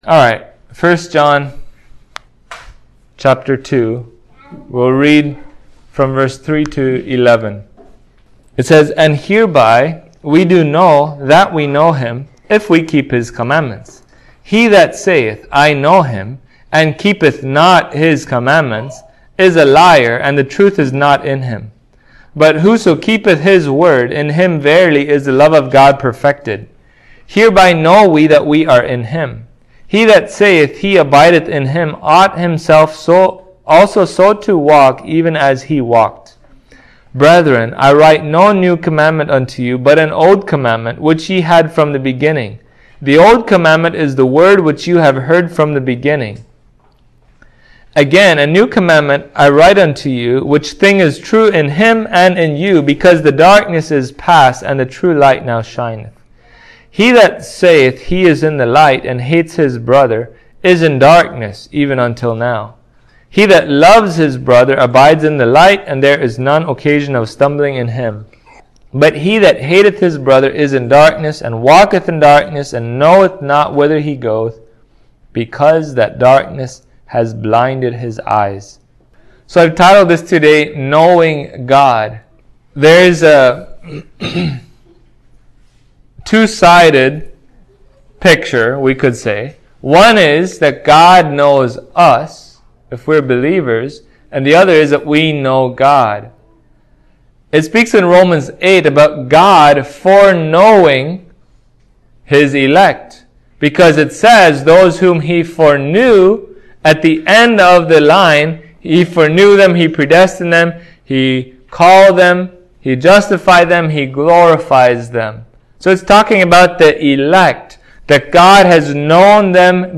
1 John 2:3-11 Service Type: Sunday Morning To truly know God is to put one’s faith in Christ and keep His commandments.